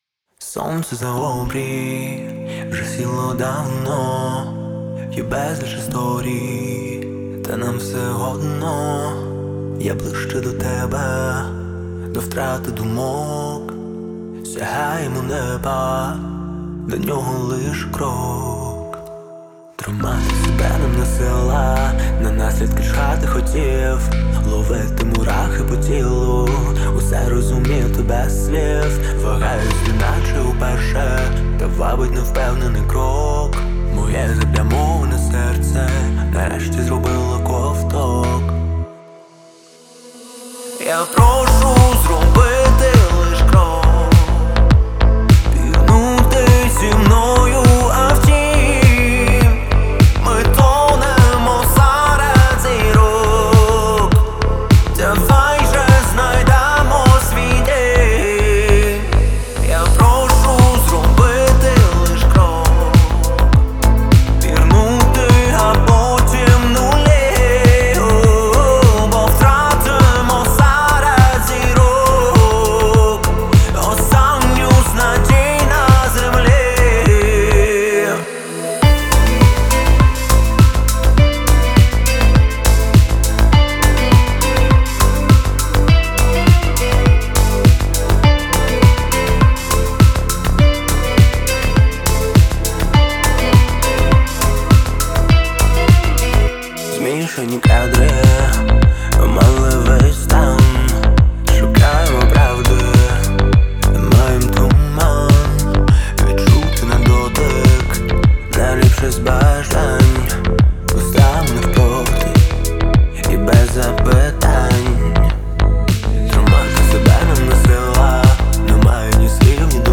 гармоничными гитарными рифами и мощным вокалом